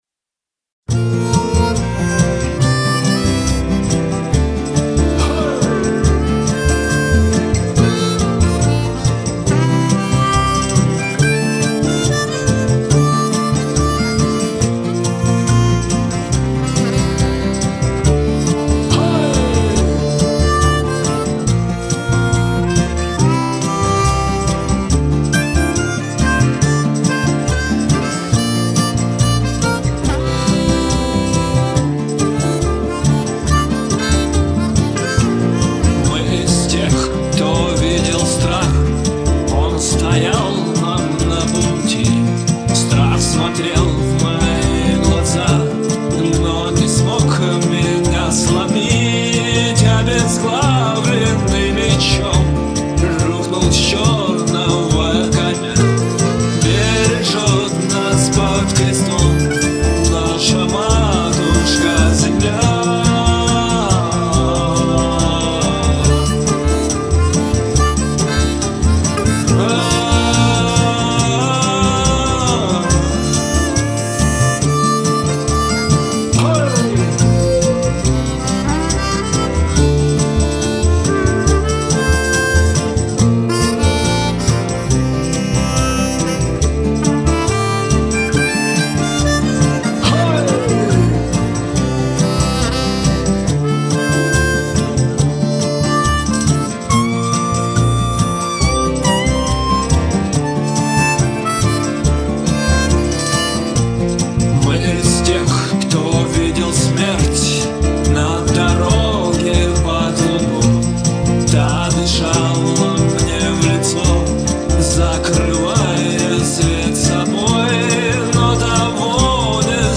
Рок или баллады
Итак по инструменталу -закрыто всё, позже буду перепевать вокал ...как потом это сводить я х.з... всё вместе играет, всего много и монотонно, предлагаю разнообразить партии инструментов, вот набросок, бас, ударные и вокал :Dle11: